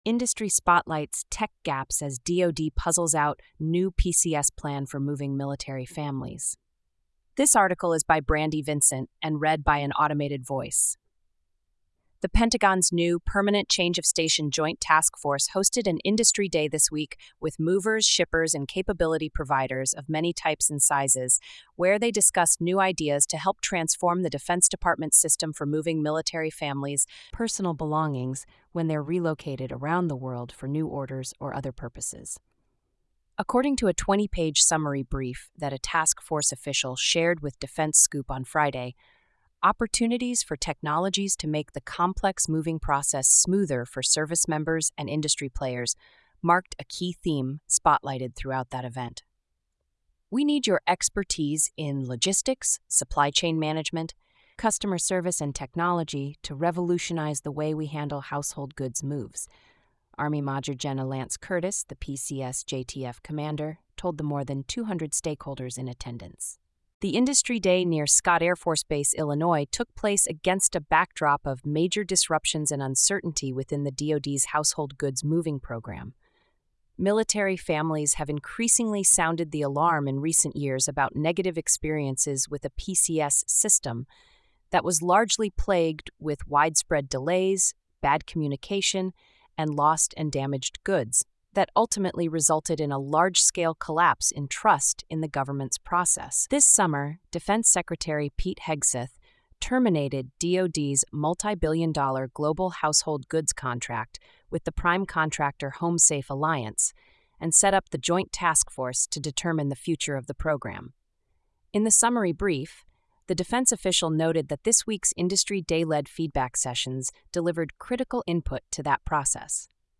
This feature uses an automated voice, which may result in occasional errors in pronunciation, tone, or sentiment.